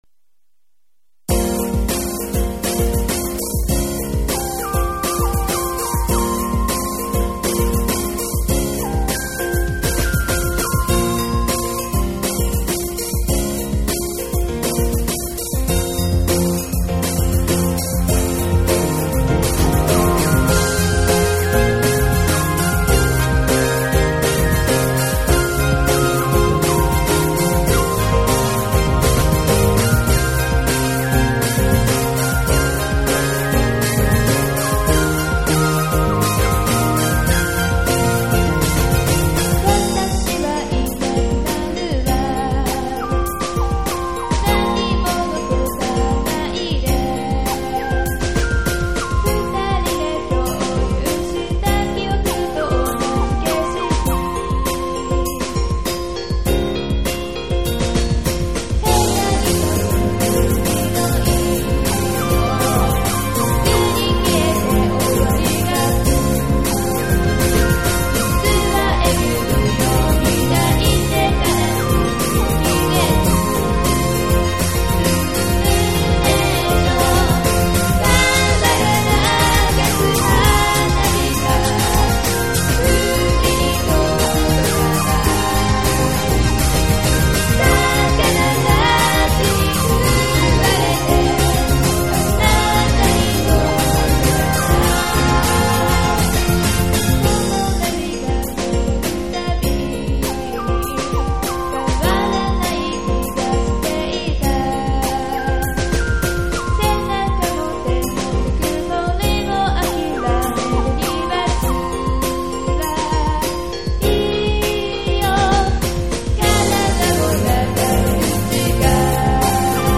music compositions
SY85 is an all-in-one synthesizer produced by YAMAHA in 1992.
My first and longest experience of multitrack sequencer was on SY85.